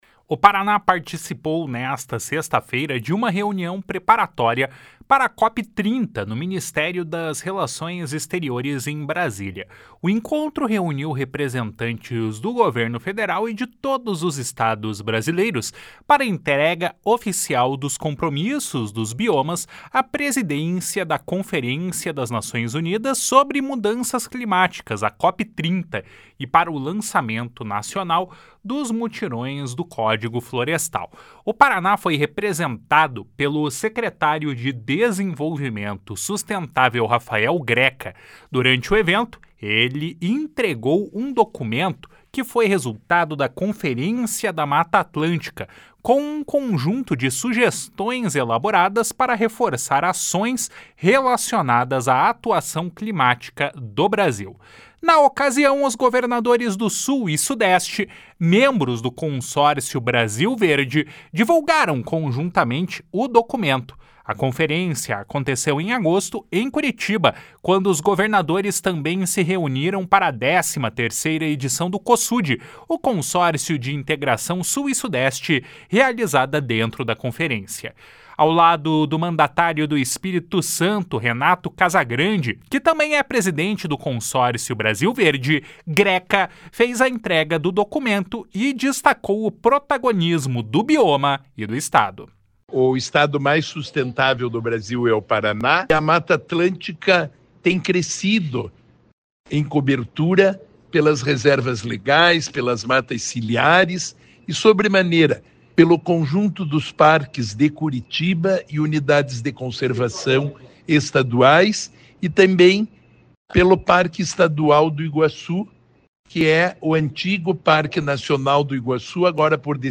// SONORA RAFAEL GRECA //